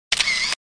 拍照声-单.mp3